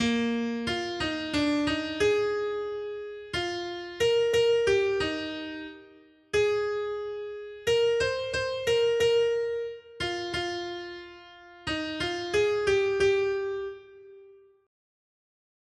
Noty Štítky, zpěvníky ol437.pdf responsoriální žalm Žaltář (Olejník) 437 Skrýt akordy R: Jak miluji tvůj zákon, Hospodine. 1.